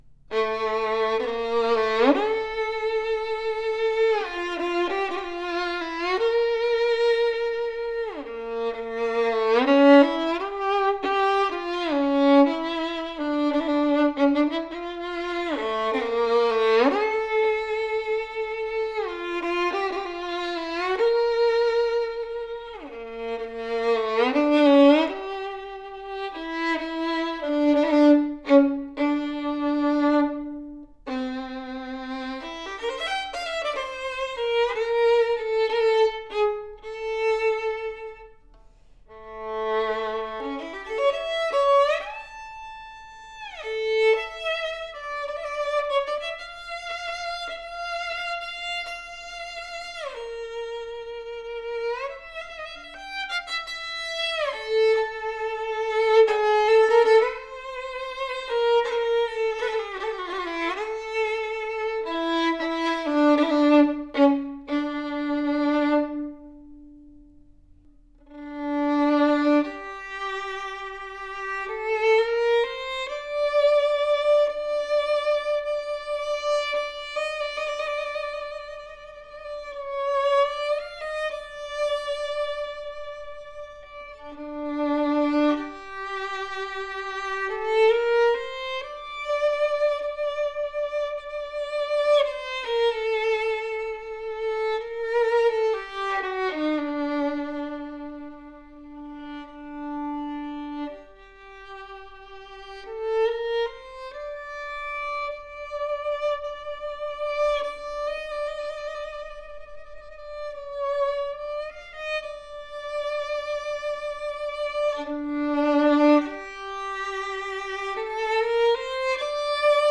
Our best selling 1743 “Cannone” del Gesu, with the warm, deep, and rich texture that serious players look for, a really warm and smooth sounding violin as the audio/video represents, with deep rich voice across all four strings.